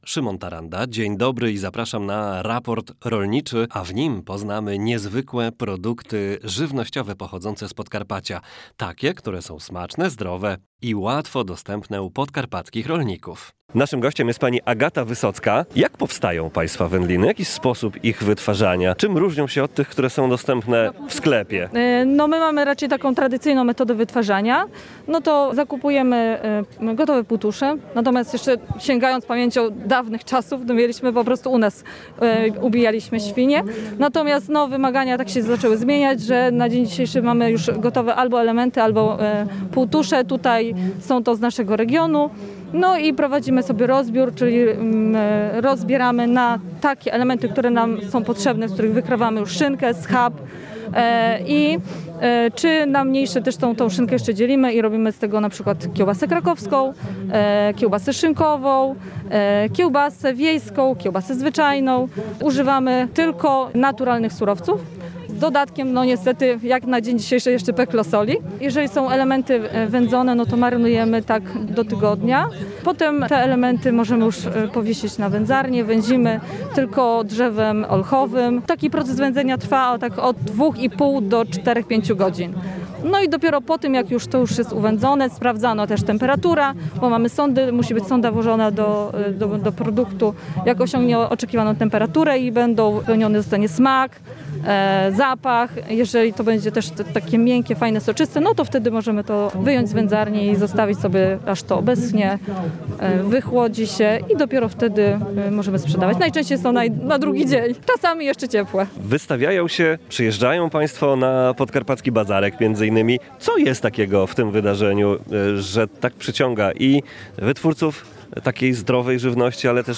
rozmawia z jedną z wytwórczyń takich produktów spożywczych